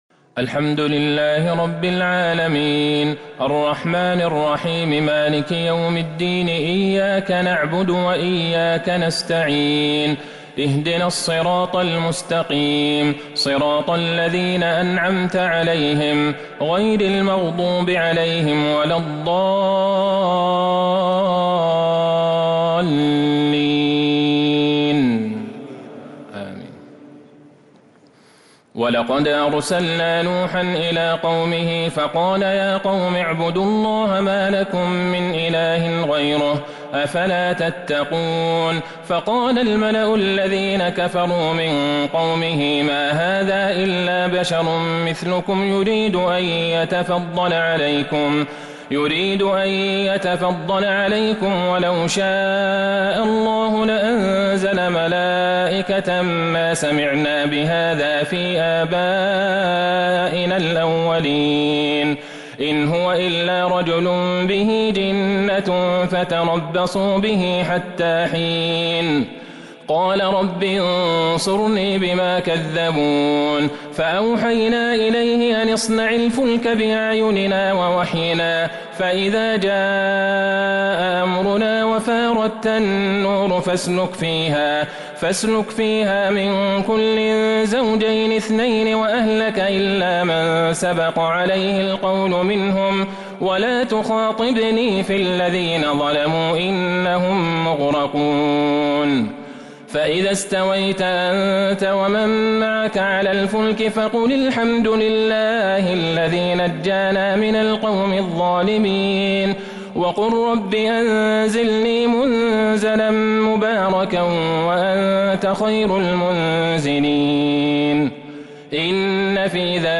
صلاة التراويح | ليلة ٢٢ رمضان ١٤٤٢هـ | سورة المؤمنون ( ٢٣ - آخرها ) | Taraweeh 22st night Ramadan 1442H > تراويح الحرم النبوي عام 1442 🕌 > التراويح - تلاوات الحرمين